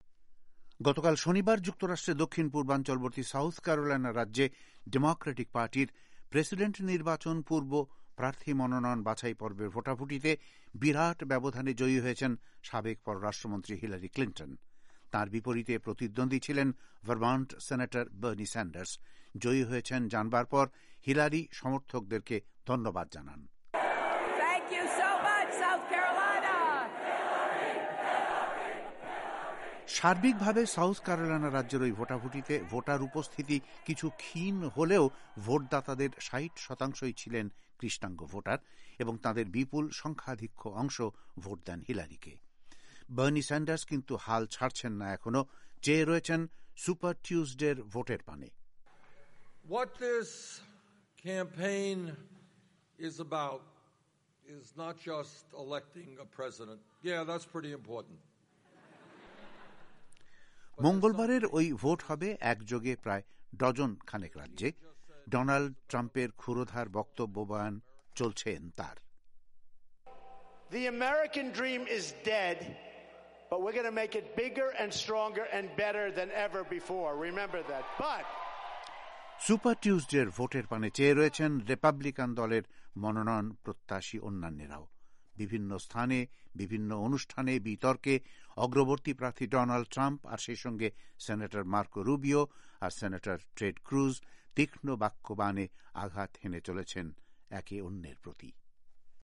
ভয়েস অফ এ্যামেরিকার রিপোর্ট: